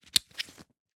pickup_sound.ogg